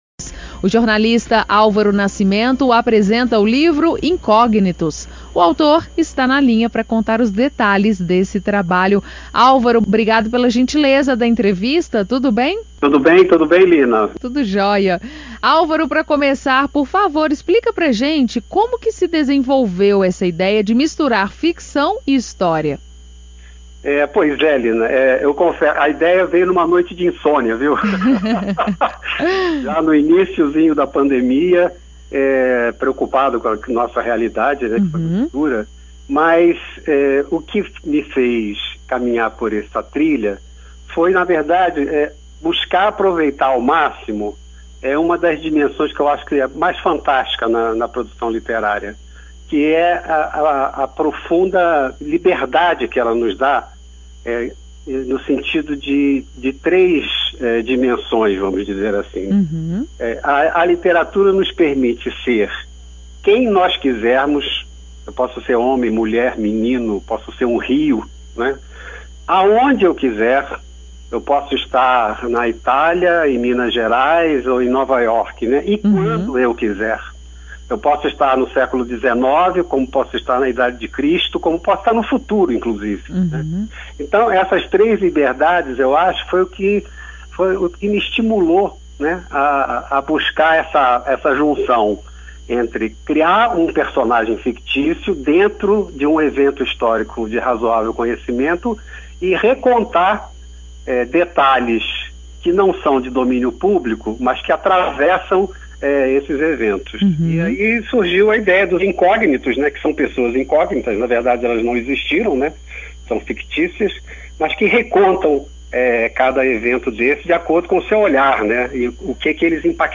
Entrevista à Rádio América AM 750 No desafio de prender o leitor em um ritmo agradável
entrevista-na-radio.mp3